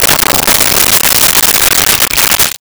Pour Coffee 02
Pour Coffee 02.wav